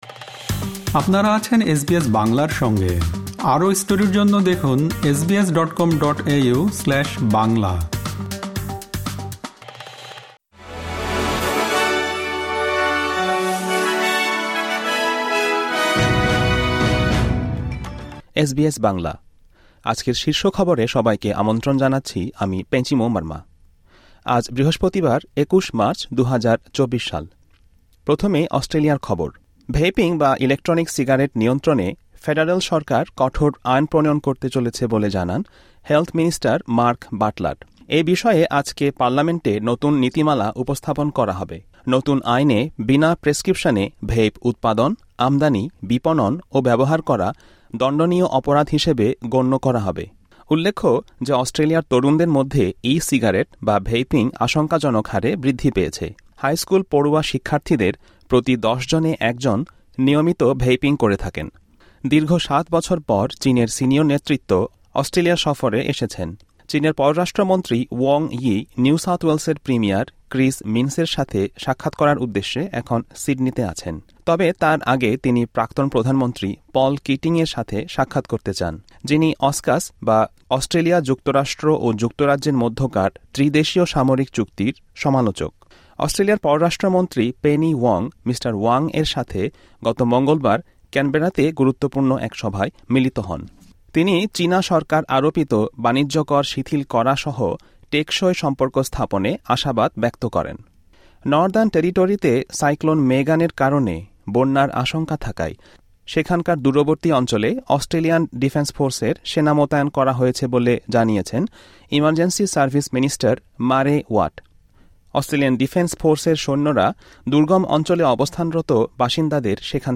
এসবিএস বাংলা শীর্ষ খবর: ২১ মার্চ, ২০২৪